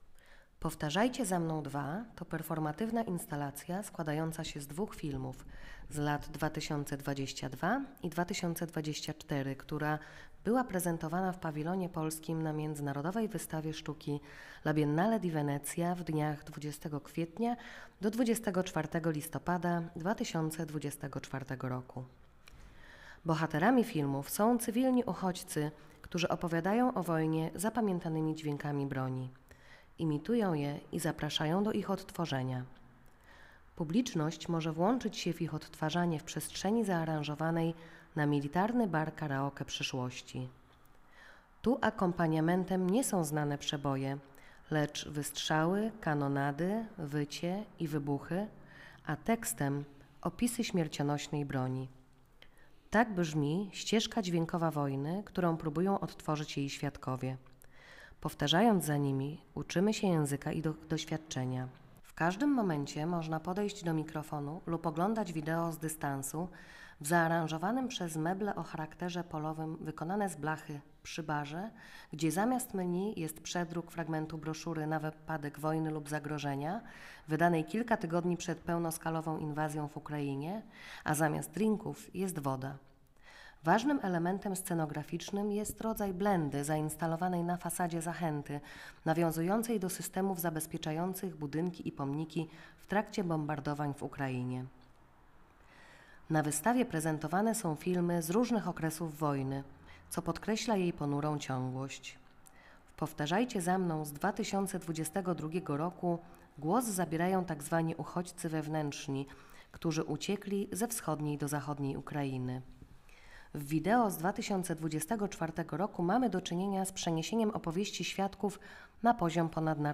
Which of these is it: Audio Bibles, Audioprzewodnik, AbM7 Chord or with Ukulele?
Audioprzewodnik